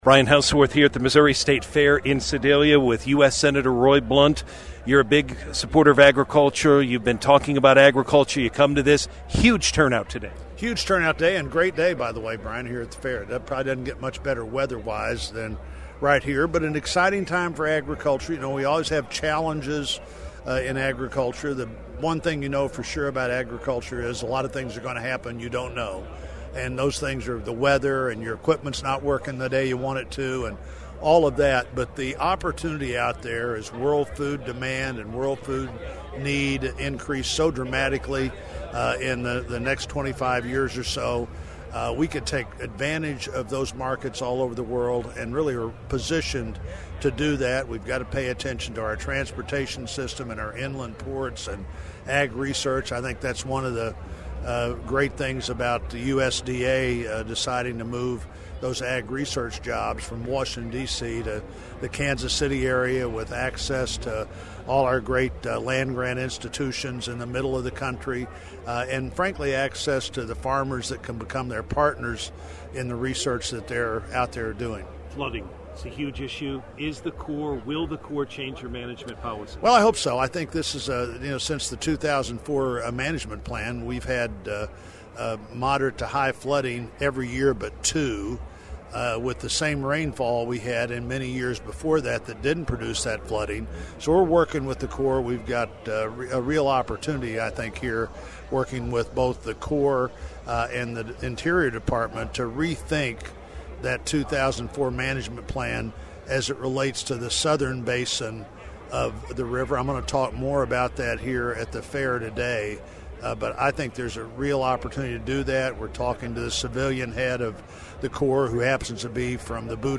U.S. Sen. Roy Blunt (R) spoke to Missourinet at the governor’s ham breakfast Thursday in west-central Missouri’s Sedalia.